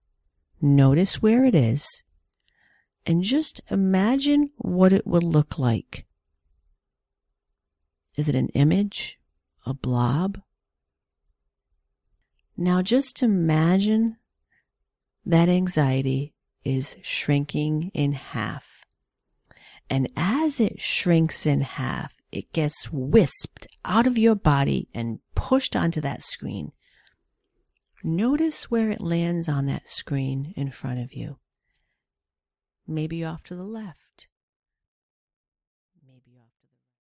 Here are a few clips…some have music in the background, some do not.